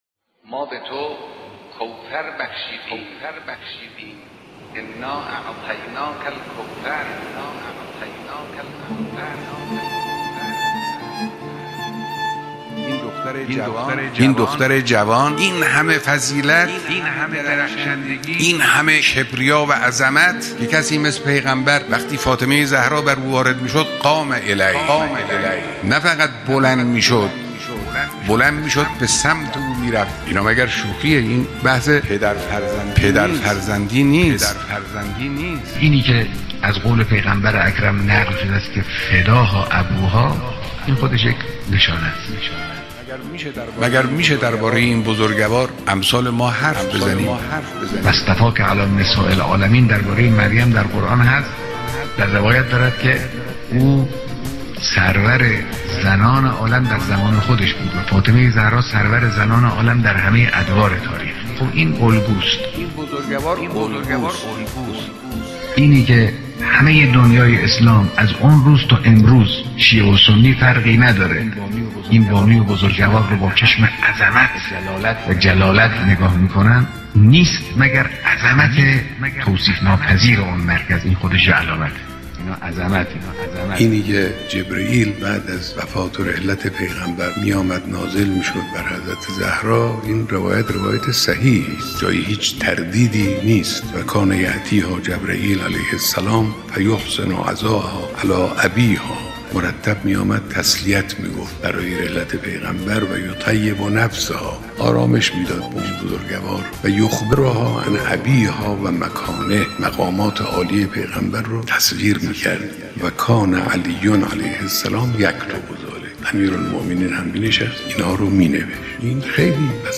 بیانات رهبر انقلاب درباره عظمت شخصیت حضرت فاطمه زهرا (س)؛